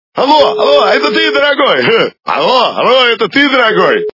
» Звуки » Люди фразы » ало, ало! - Это ты дорогой?
При прослушивании ало, ало! - Это ты дорогой? качество понижено и присутствуют гудки.